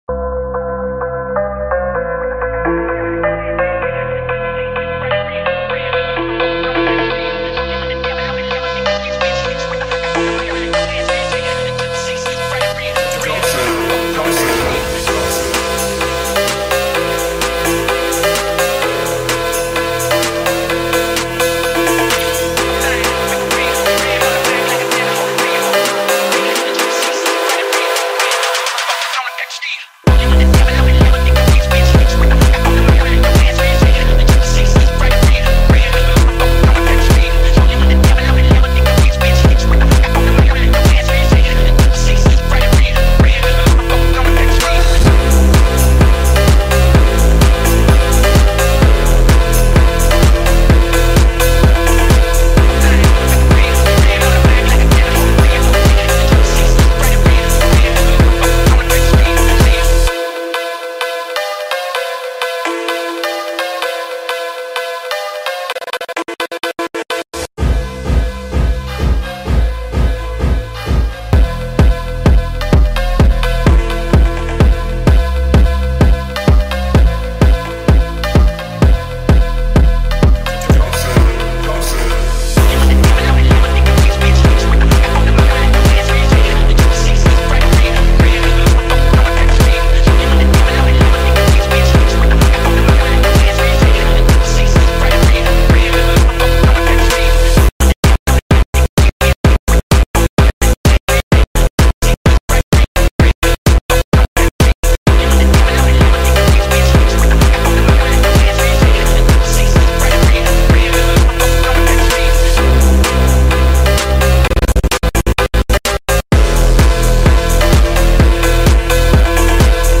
Phonk House Mix